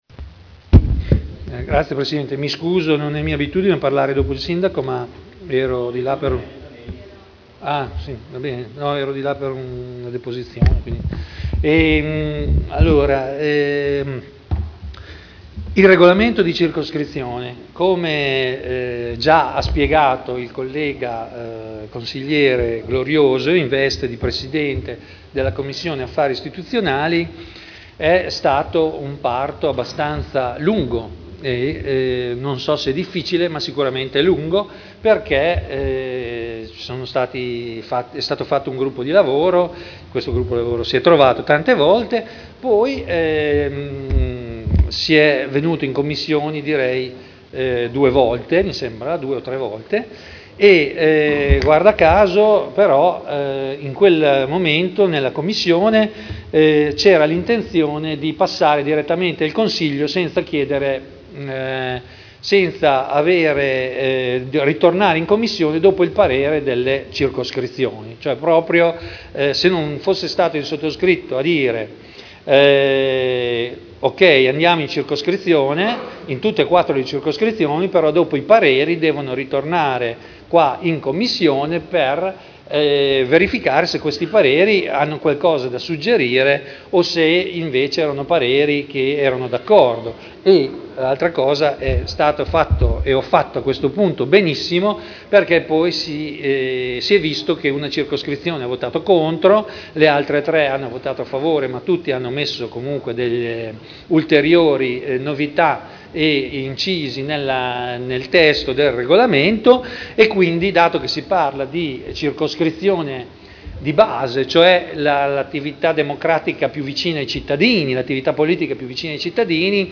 Seduta del 14/07/2011. Dibattito su delibera: Regolamento dei Consigli di Circoscrizione – Modifica (Commissione Affari Istituzionali del 13 maggio 2011 e del 6 luglio 2011)